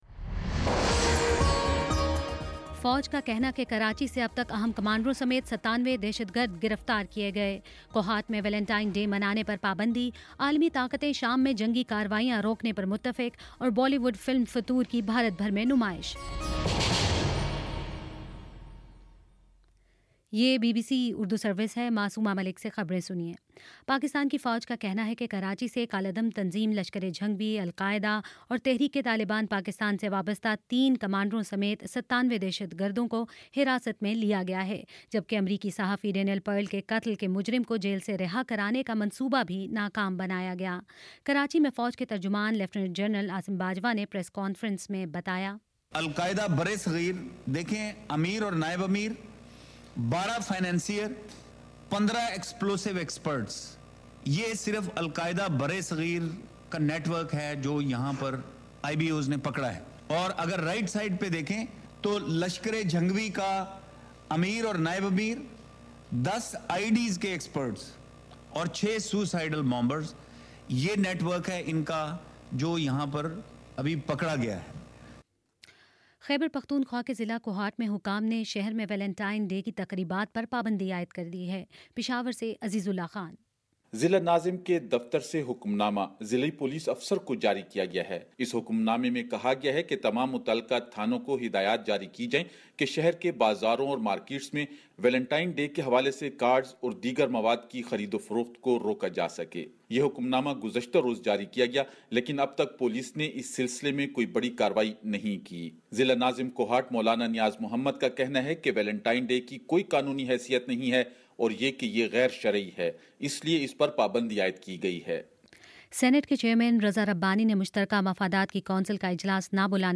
فروری 12 : شام سات بجے کا نیوز بُلیٹن